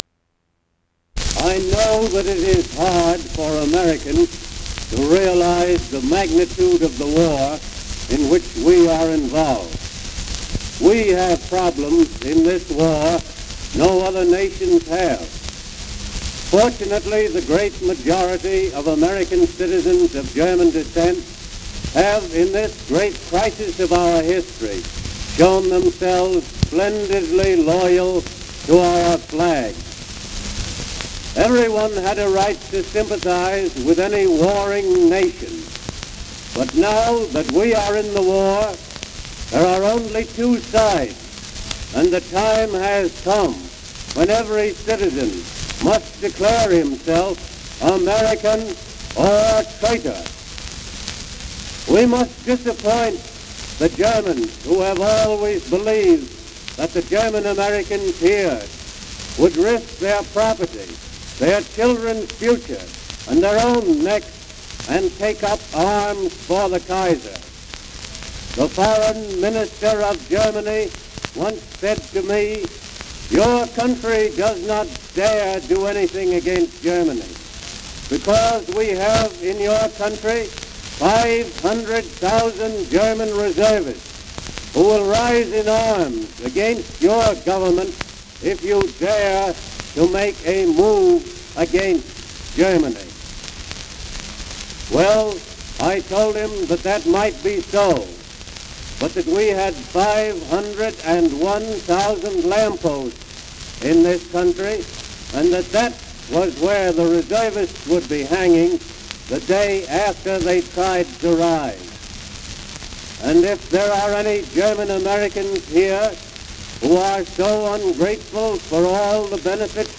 American ambassador to Germany James W. Gerard speaks about the duty of German-Americans during war time.
Recorded by Nation's Forum, 1917.